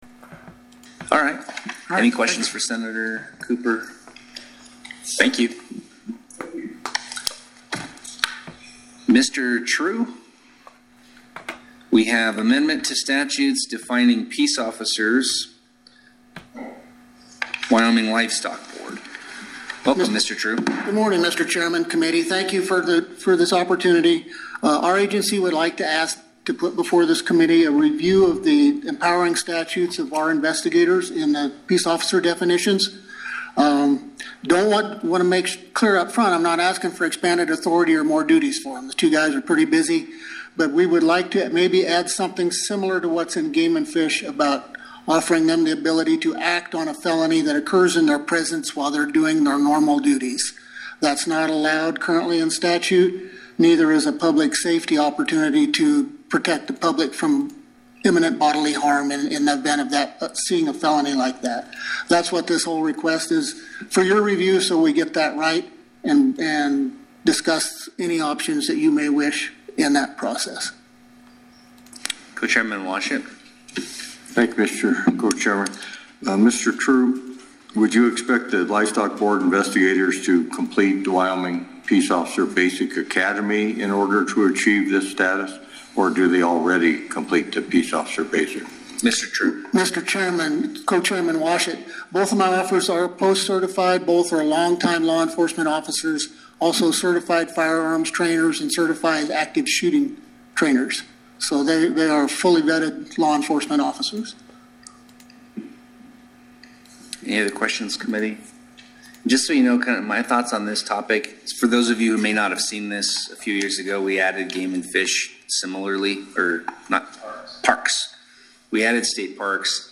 In March 2026 the Judiciary Committee was approached by a person claiming to represent the Livestock Board asking the committee to grant peace officer authority to their investigators.  Judiciary Committee Chair Olsen responded by inviting any other agencies to consider if they have similar requests so that the legislature is not having to draft bills like this regularly.